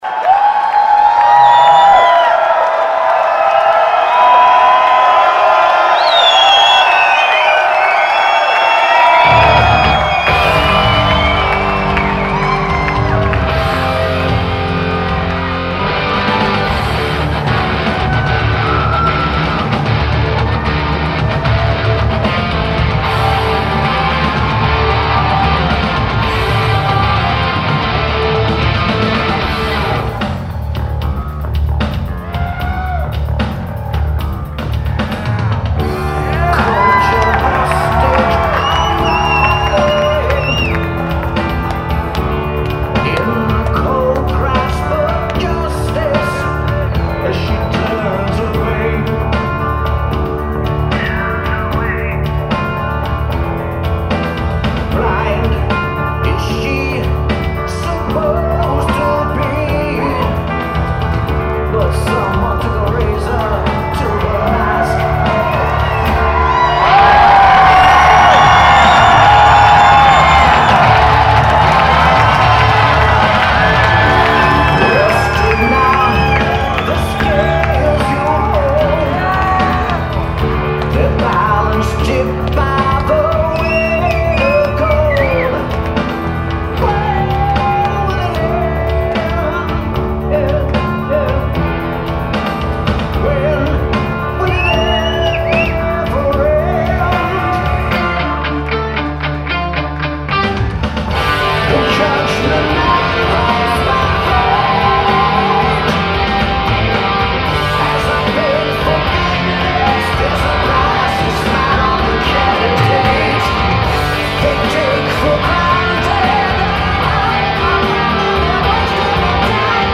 Anyway, good quality, for what they are.